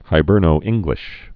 (hī-bûrnō-ĭngglĭsh)